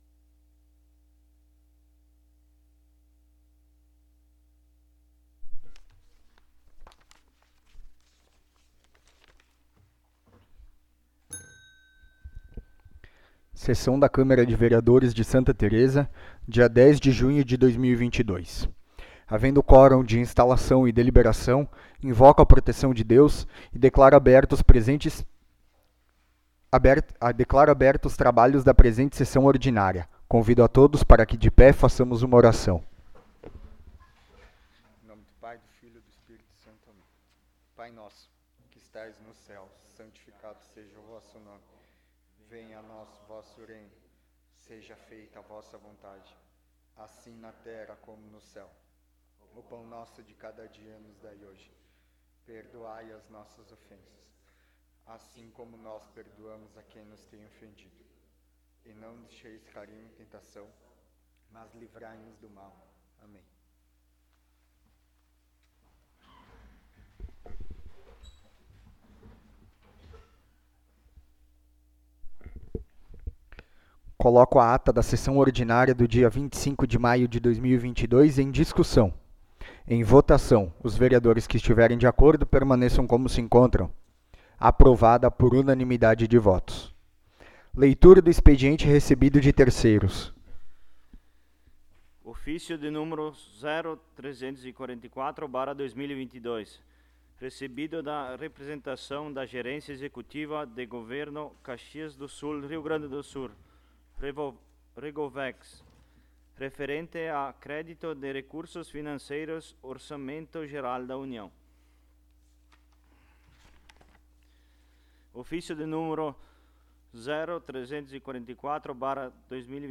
9ª Sessão Ordinária de 2022
Local: Plenário Pedro Parenti